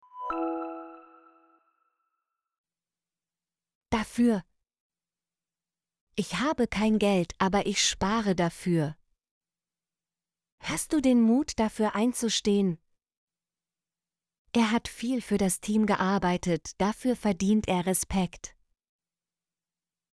dafür - Example Sentences & Pronunciation, German Frequency List